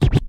50 Scratch FX.wav